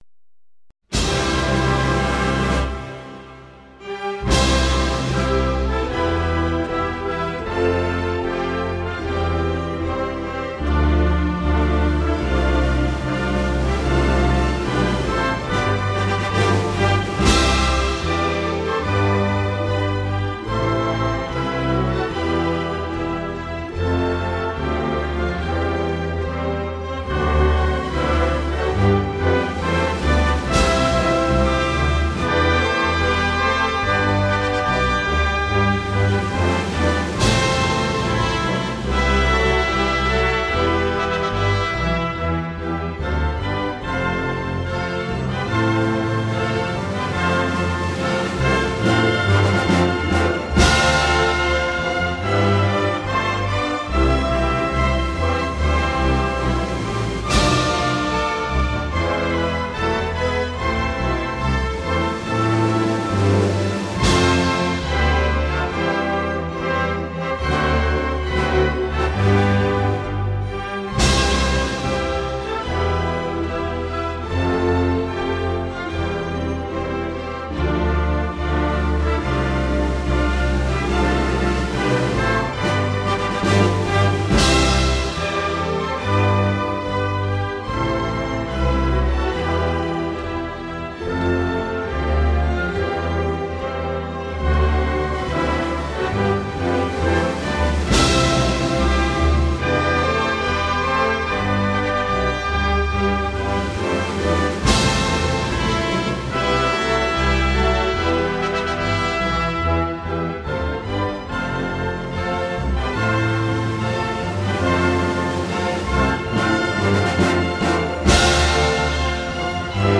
минус (караоке)